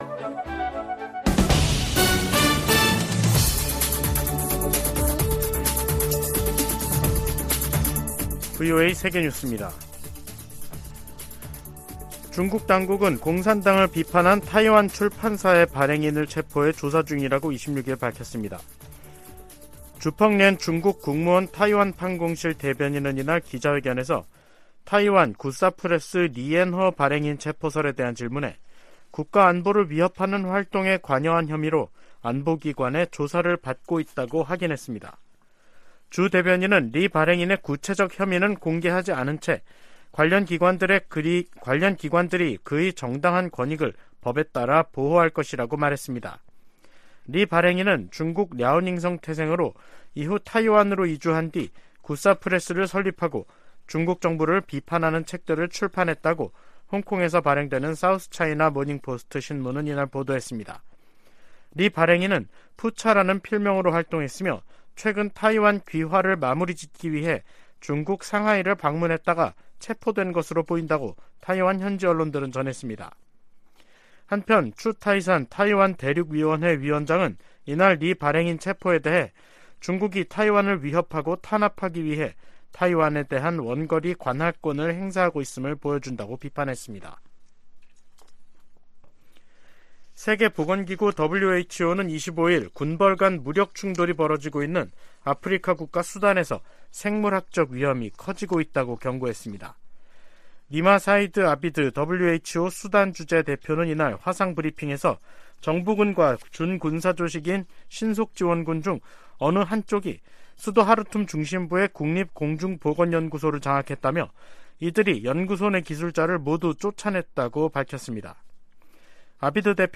VOA 한국어 간판 뉴스 프로그램 '뉴스 투데이', 2023년 4월 26일 3부 방송입니다. 윤석열 한국 대통령이 미국 국빈 방문 이틀째 한국전 참전용사 기념비 참배와 항공우주국 방문 등 일정을 보냈습니다. 미 정부 고위당국자는 미한 정상이 26일 미국의 확장억제 공약을 강화하고 한국의 비확산 의무를 재확인하는 선언을 발표할 것이라고 밝혔습니다.